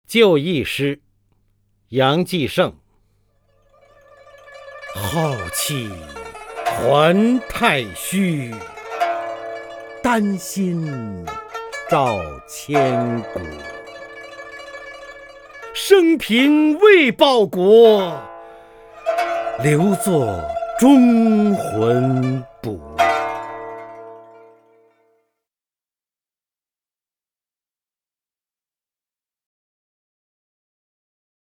方明朗诵：《就义诗》(（明）杨继盛) （明）杨继盛 名家朗诵欣赏方明 语文PLUS
（明）杨继盛 文选 （明）杨继盛： 方明朗诵：《就义诗》(（明）杨继盛) / 名家朗诵欣赏 方明